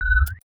Universal UI SFX / Clicks
UIClick_Soft Tonal 04.wav